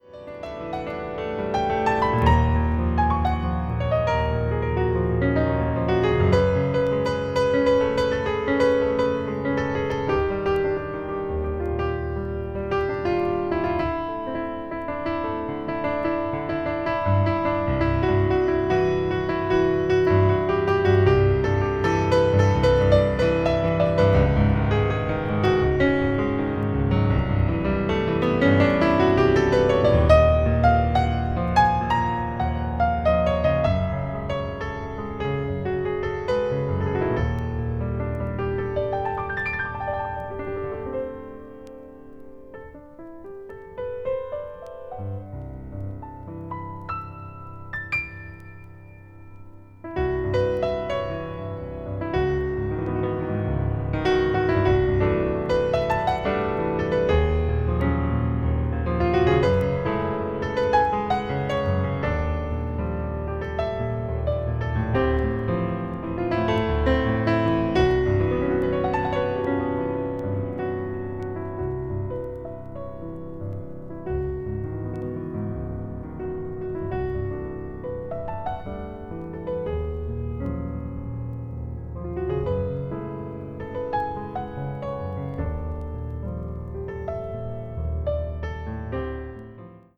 media : EX+/EX+(some slightly noises.)